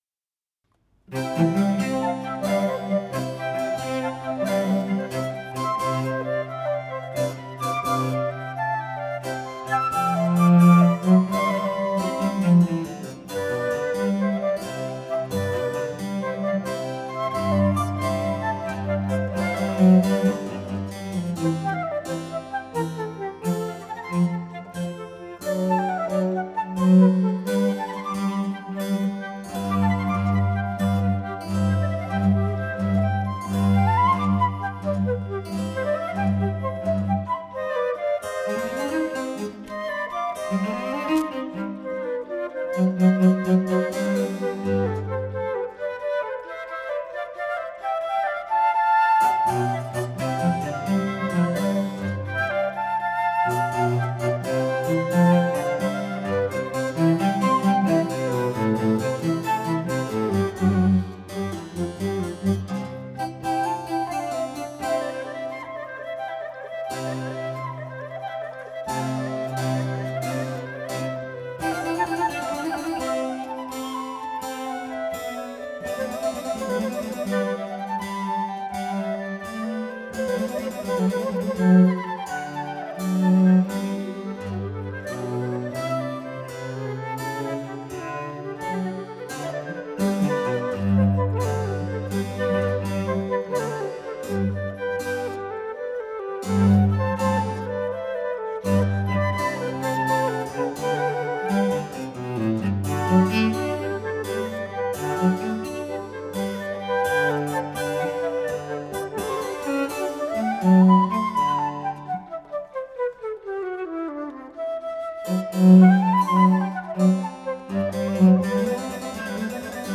Incamto ensemble | Live recording | InCamTo
flauti
clavicembalo
violoncello
Chiesa di Santa Chiara, Torino